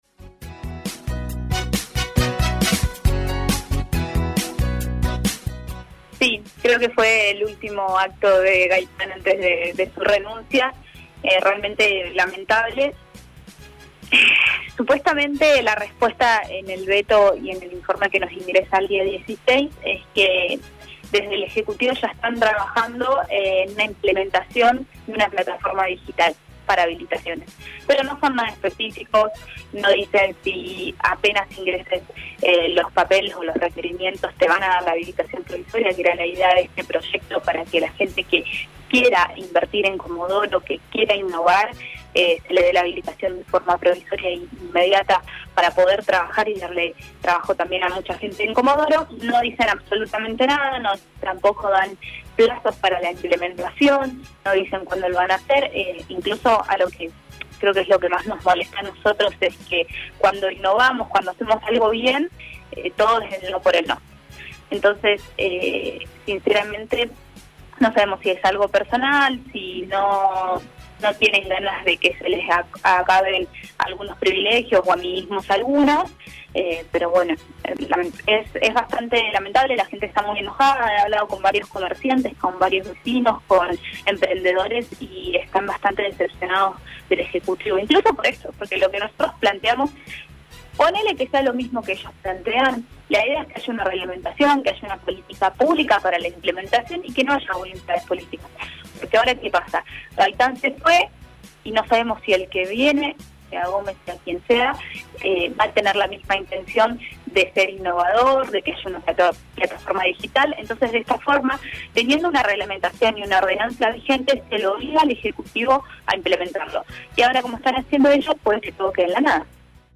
Así lo expresaba en el aire de LA MAÑANA DE HOY, la concejal Ximena González: